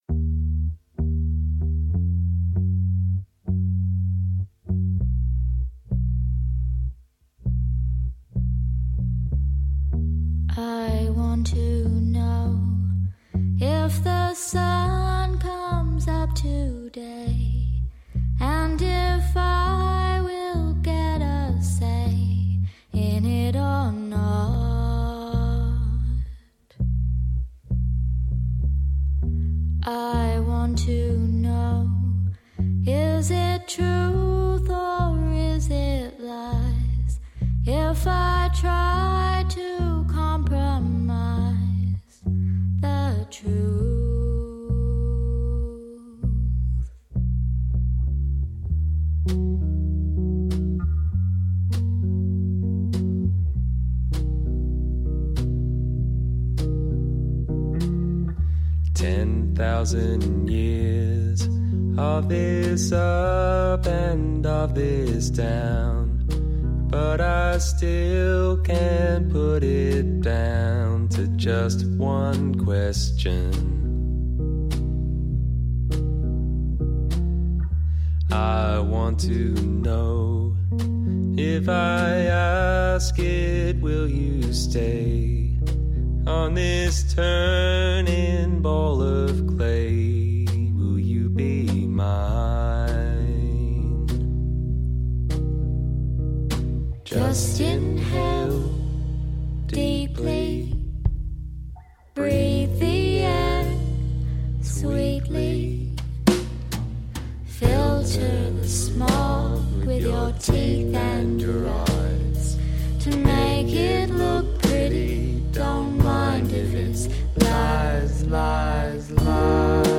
alternating lead vocalists